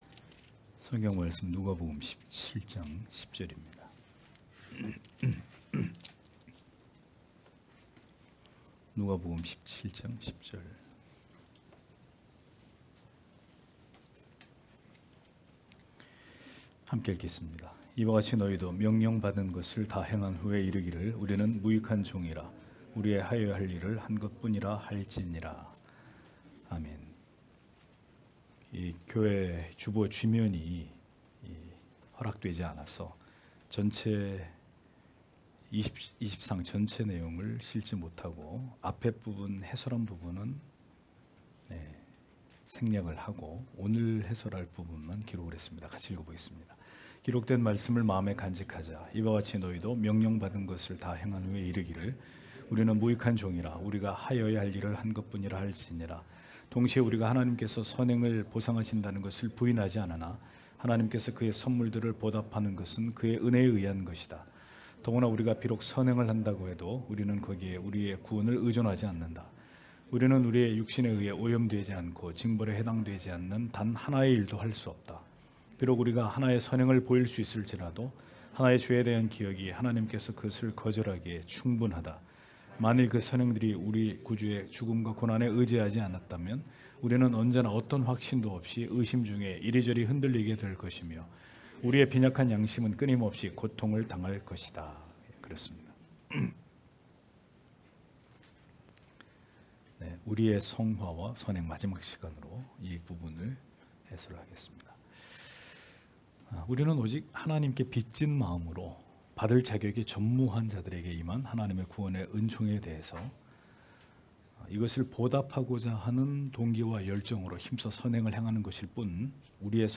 주일오후예배 - [벨직 신앙고백서 해설 27] 제24항 우리의 성화와 선행(3) (눅 17장 10절)